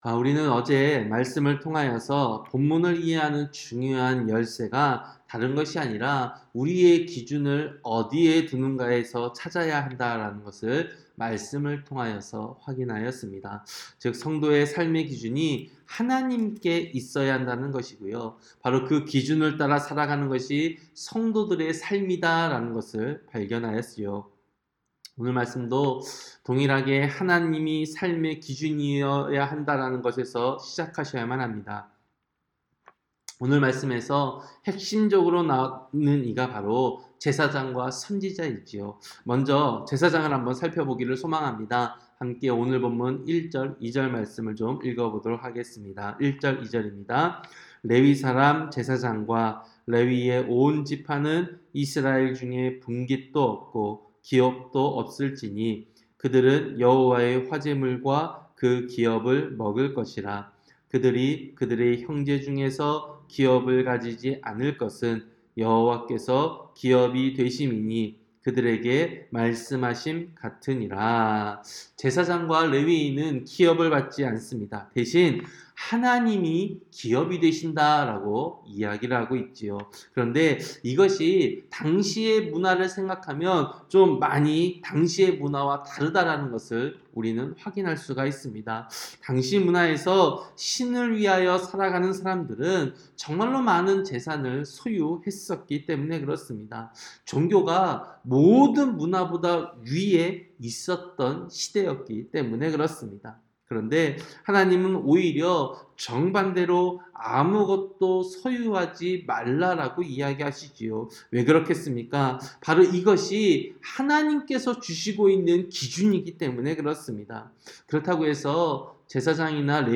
새벽설교-신명기 18장